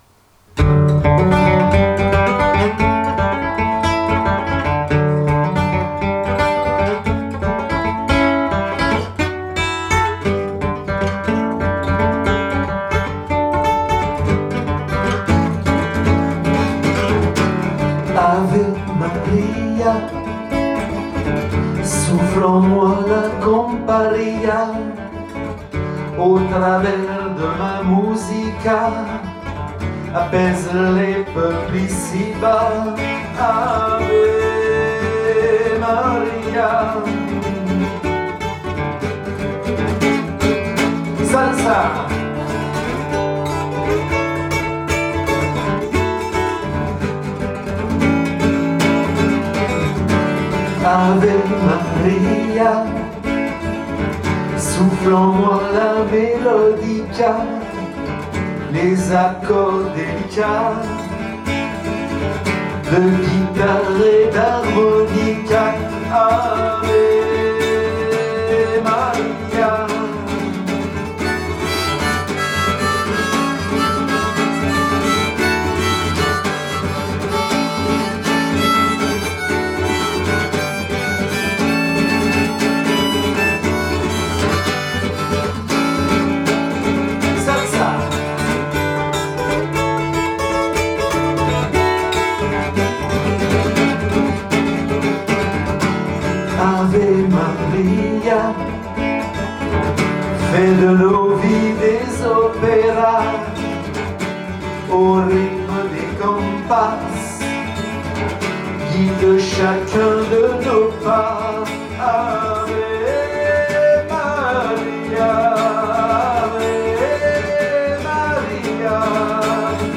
Une rueda salsa révélatrice où la danse